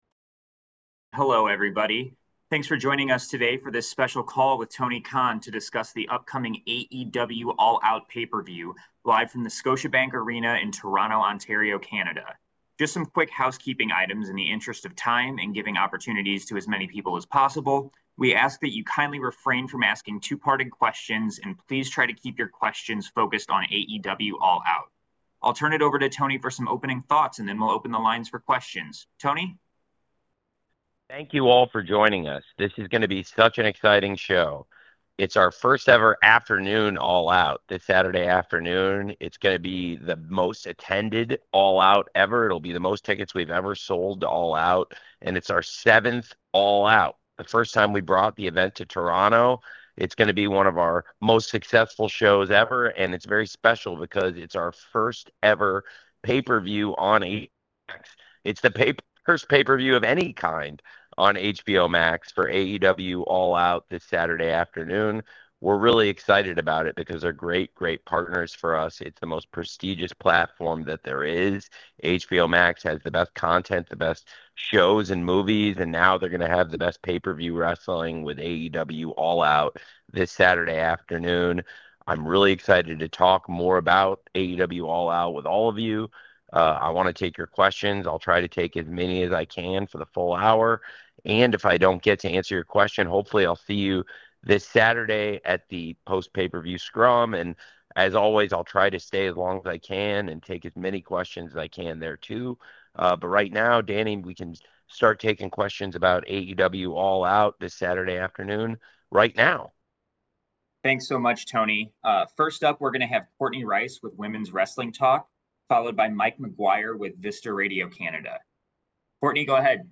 Tony Khan, AEW President, General Manager and Head of Creative, spoke to the media on Thursday September 18 to discuss All Out, which takes place Saturday September 20.